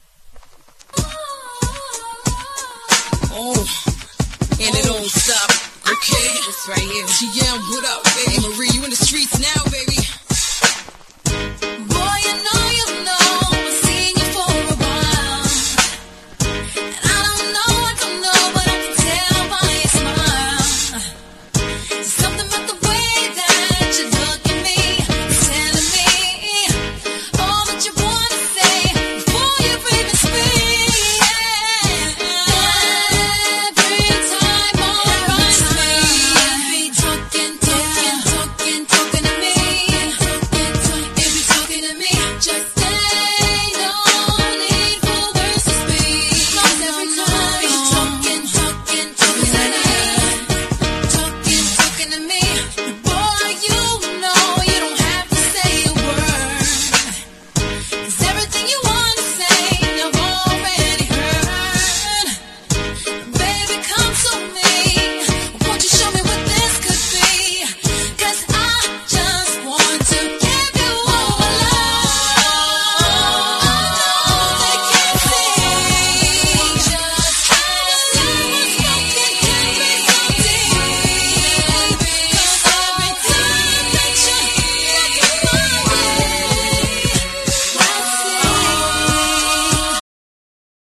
シンプルなトラックが聴きやすい、niceナンバー!!